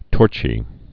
(tôrchē)